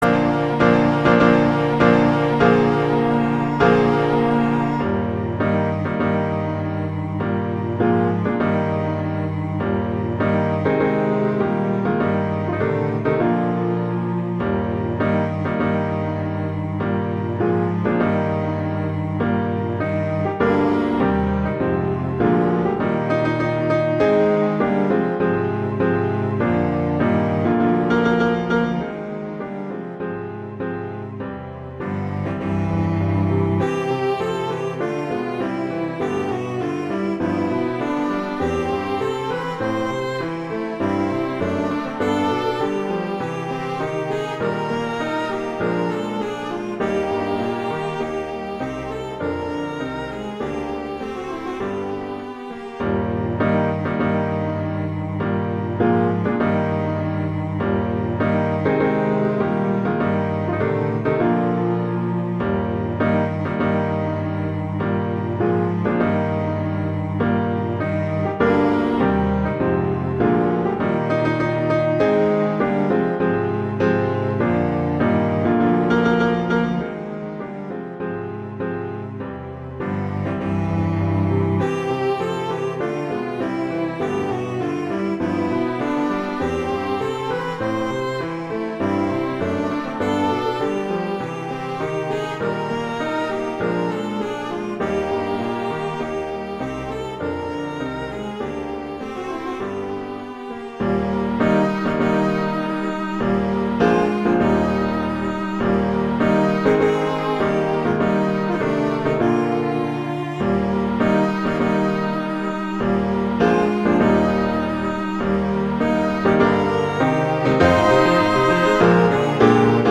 arrangements for two cellos and piano
wedding, traditional, classical, festival, love, french